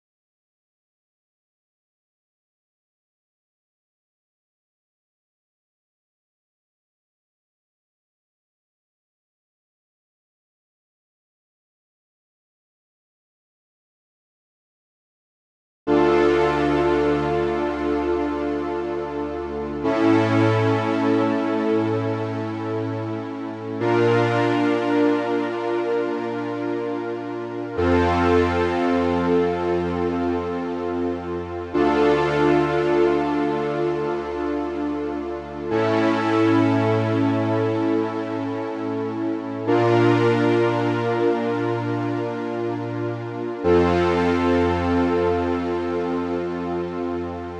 🔹 50 Premium Serum Presets crafted for melodic house, cinematic soundscapes, and deep emotional productions.
• Layered & Textured Sounds for that big cinematic feel
Preset Preview
RearView-Lights-0018-Instrument-ST-Silk-Collapse.wav